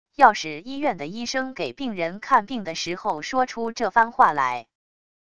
要是医院的医生给病人看病的时候说出这番话来wav音频生成系统WAV Audio Player